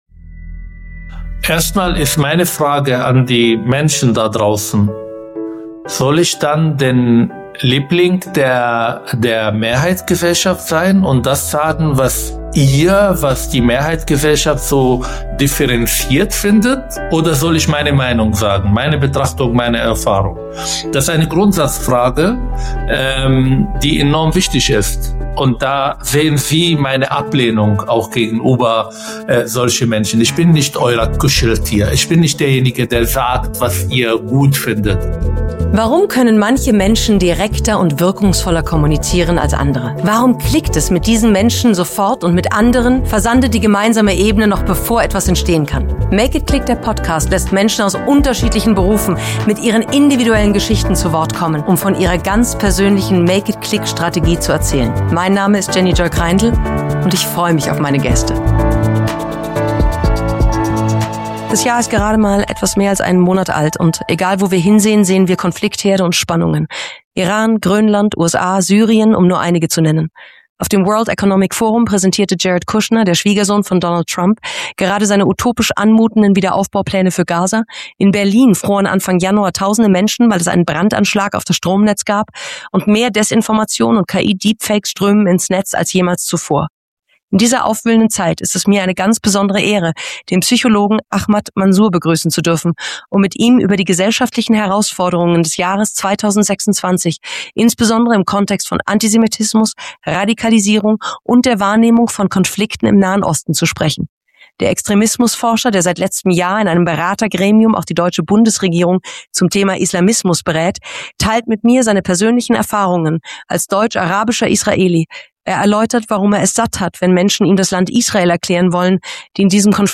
In dieser aufwühlenden Zeit ist es mir eine ganz besondere Ehre, den Psychologen und Buchautor Ahmad Mansour begrüssen zu dürfen um mit ihm über die gesellschaftlichen Herausforderungen des Jahres 2026, insbesondere im Kontext von Antisemitismus, Radikalisierung und der Wahrnehmung von Konflikten im Nahen Osten, zu sprechen.